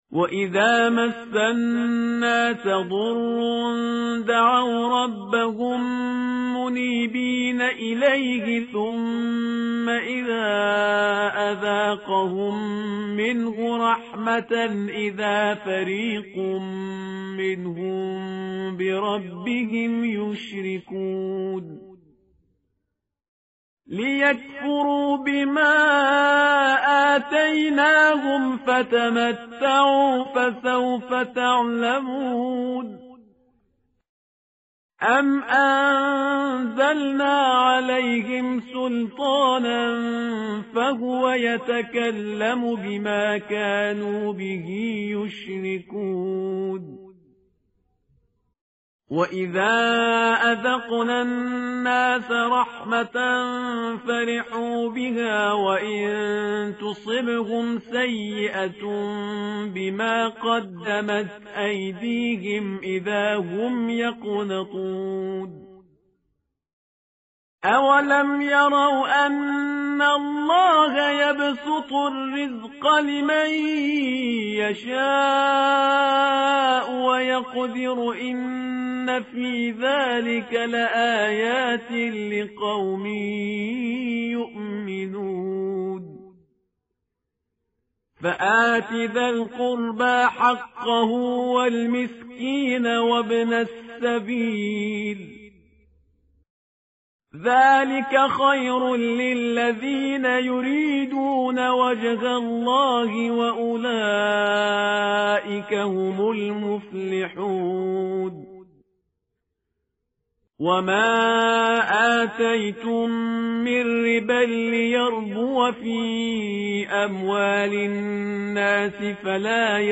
متن قرآن همراه باتلاوت قرآن و ترجمه
tartil_parhizgar_page_408.mp3